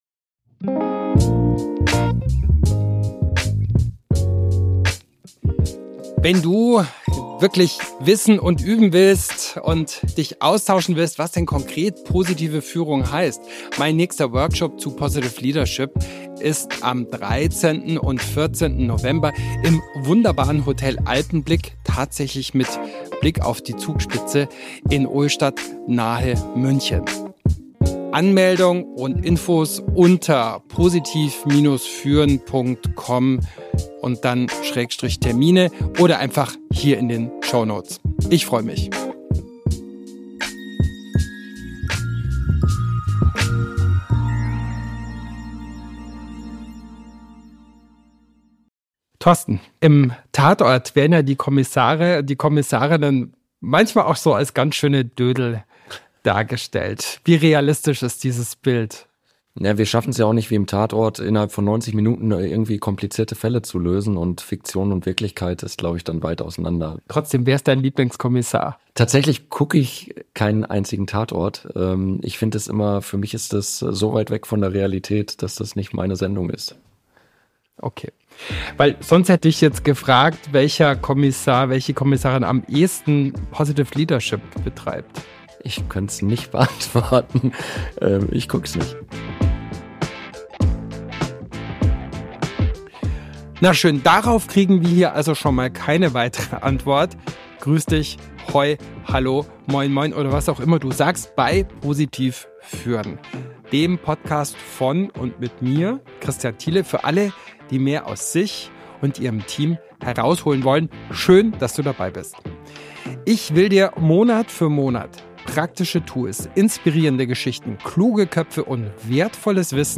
Positiv(er) führen bei der Polizei – mit Polizeipräsident Torsten Krückemeier